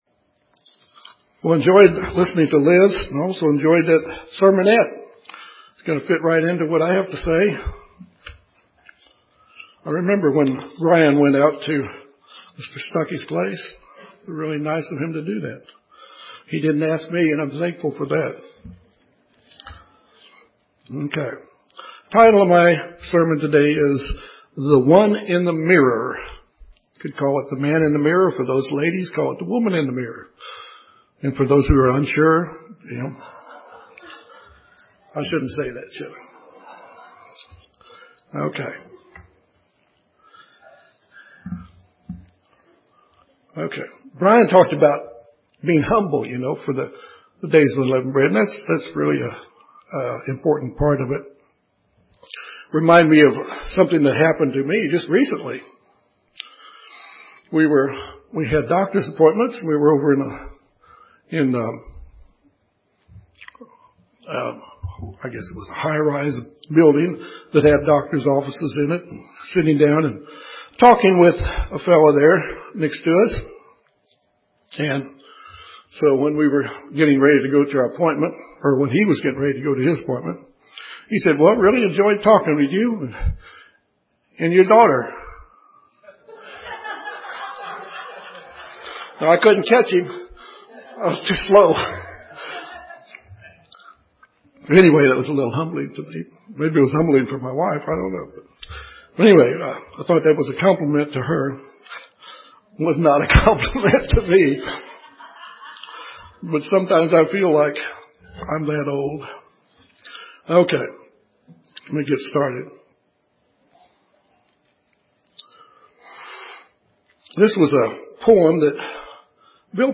This sermon discusses how God will test those whom he calls to see their depth of commitment to their calling. While testing us through difficult times, God will also make a way of our escape from those trials to those who will trust in His faithfulness to fulfill His promises.